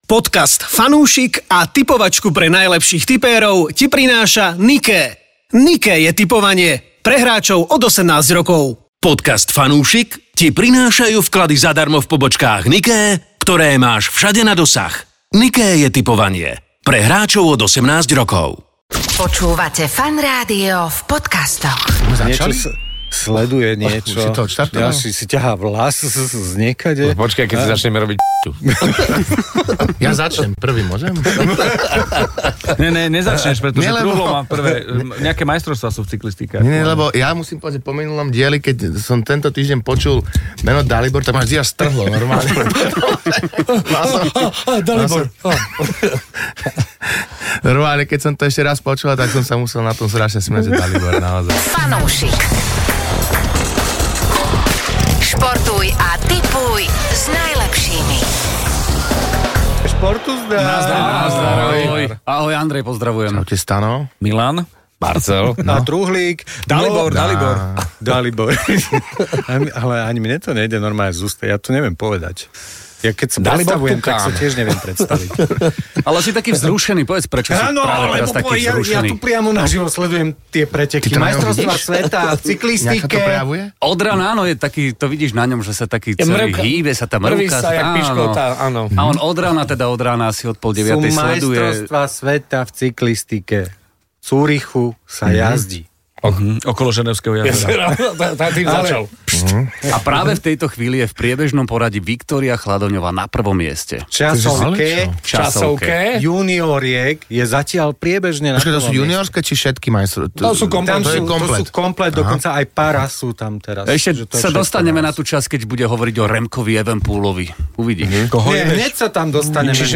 Máme vicemajsterku sveta v juniorskej cyklistike! Daj si fanúšikovskú debatku o športe a tipovaní.